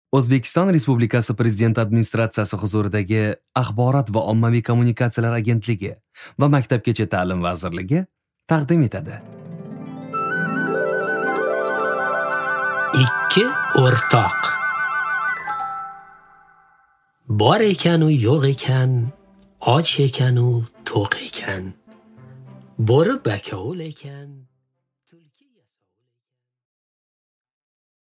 Аудиокнига Ikki o'rtoq